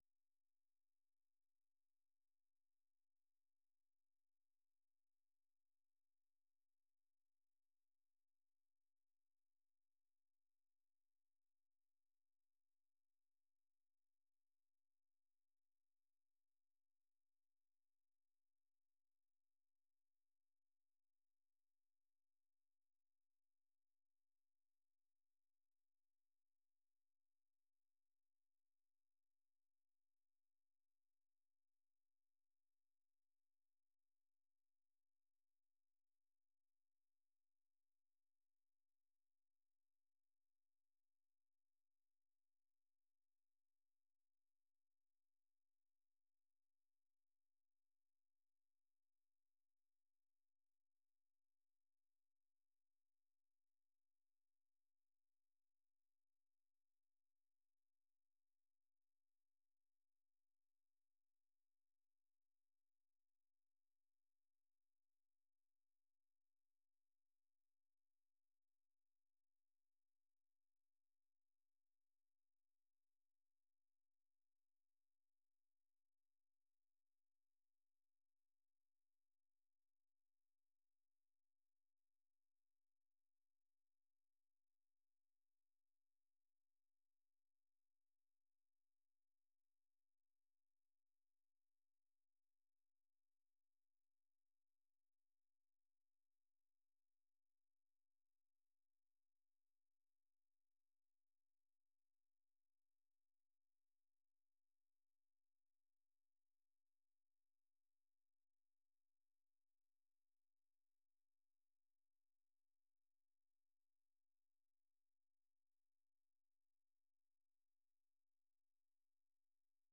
ข่าวสดสายตรงจากวีโอเอ ภาคภาษาไทย 6:30 – 7:00 น.